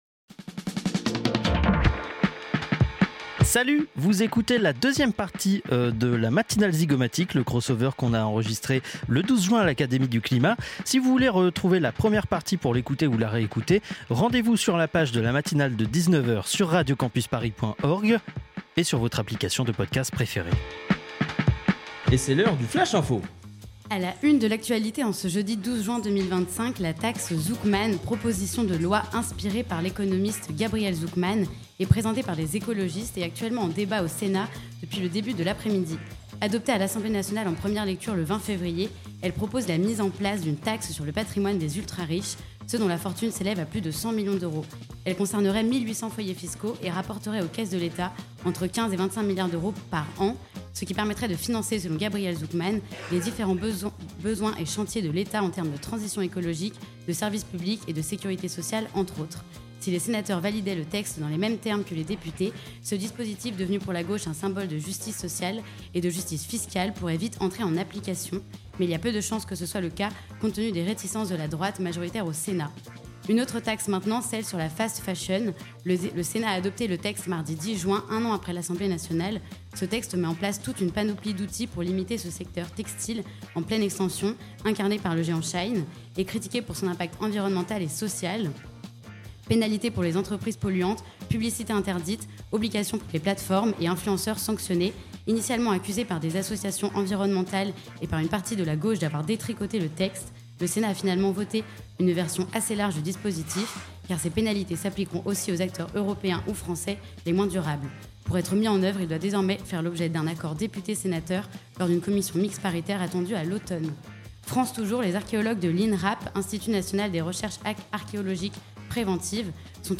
La Matinale Zygomatique en direct de l'Académie du...